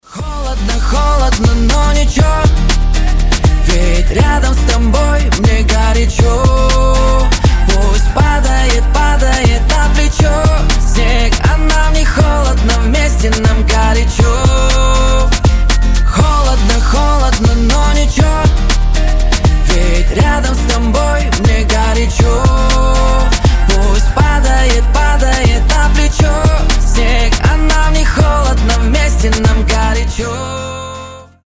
гитара
красивый мужской голос